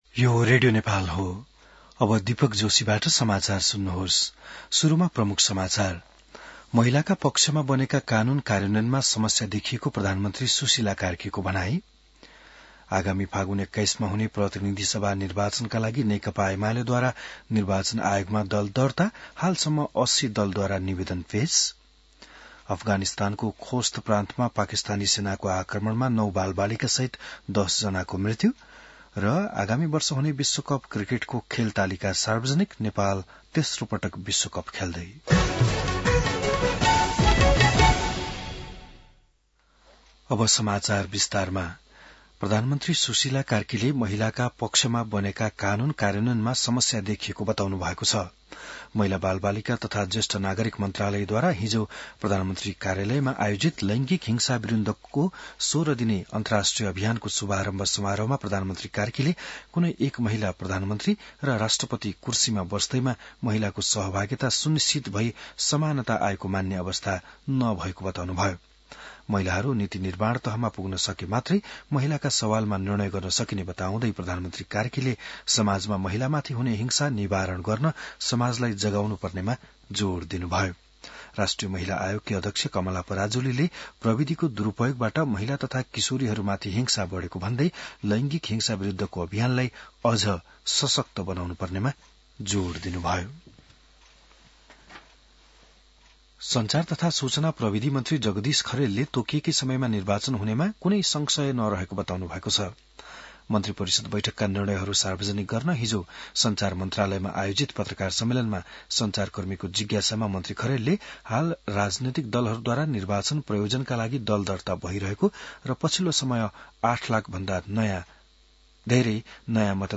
बिहान ९ बजेको नेपाली समाचार : १० मंसिर , २०८२